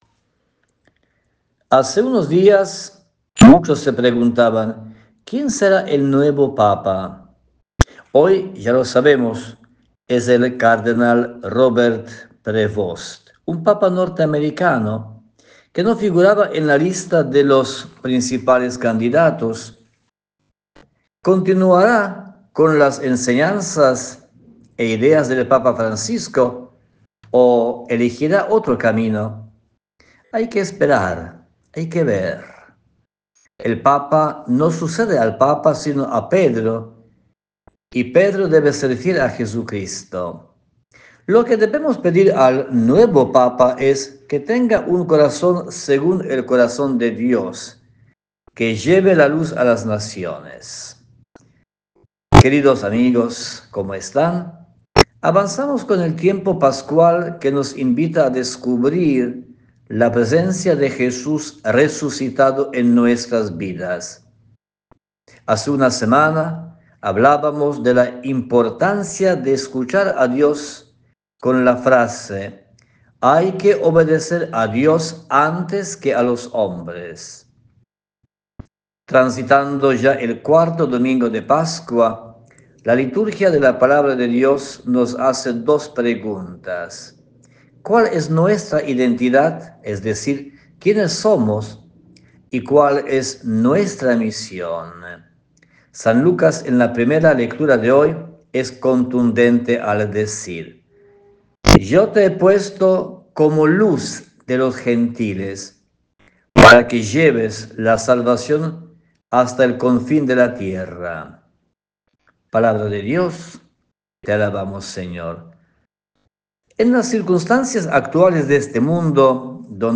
ofreció en EME su habitual reflexión espiritual